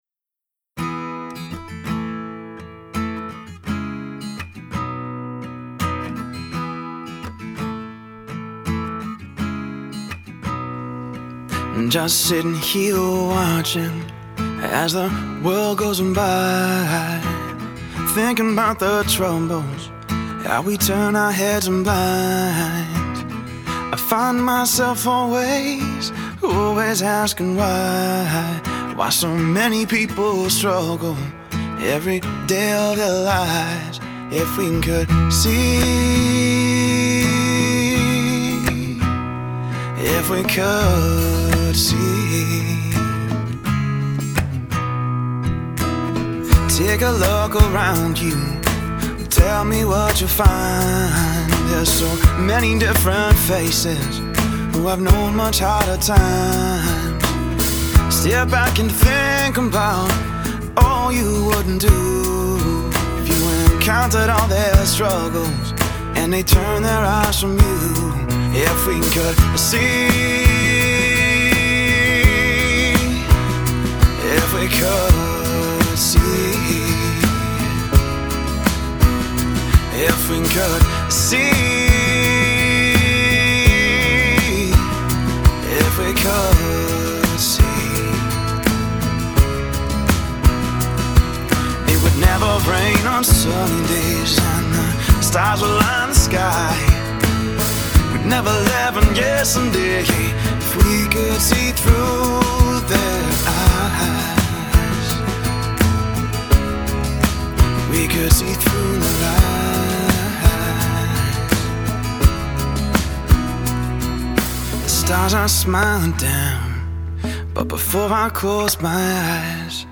a talented soul/rock singer/songwriter based in Los Angeles
soulful, expressive vocals